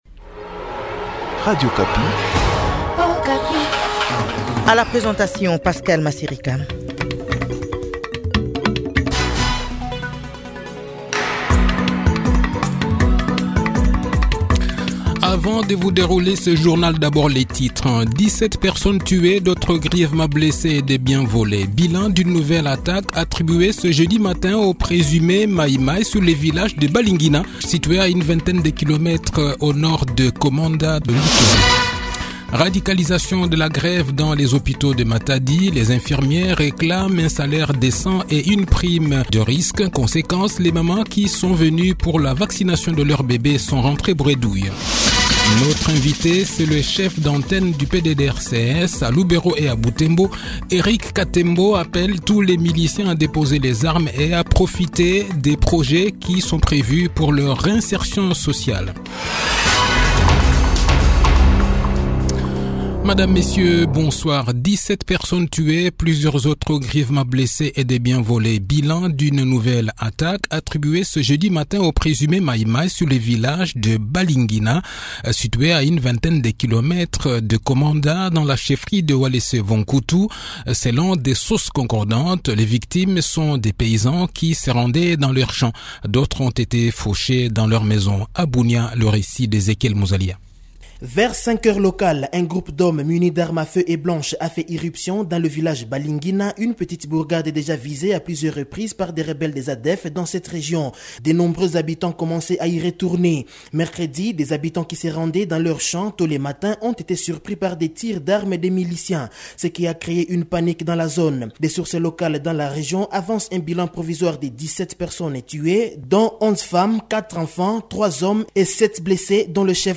Le journal de 18 h, 6 Septembre 2023